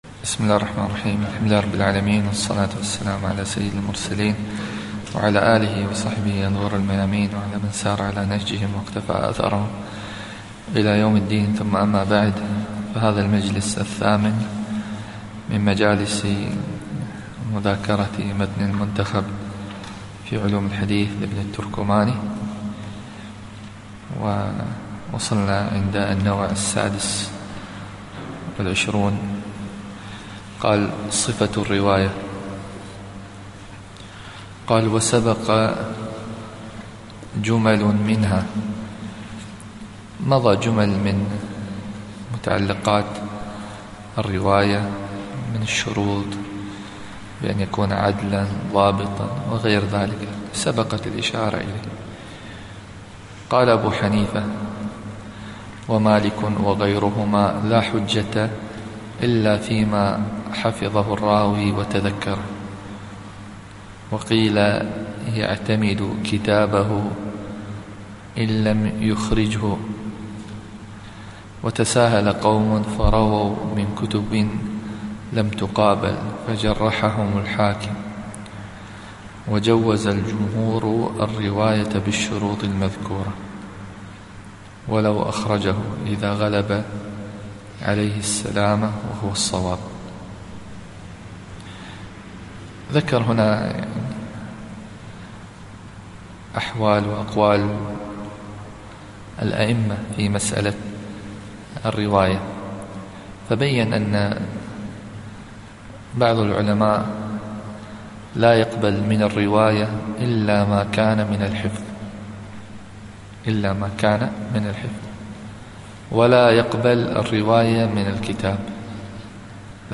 الدرس الثامن